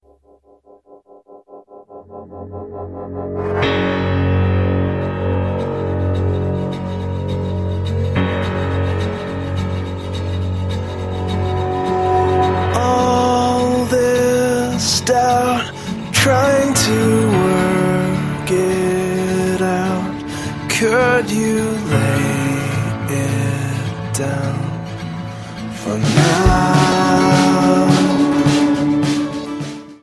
Progressive-Worship-Album
• Sachgebiet: Praise & Worship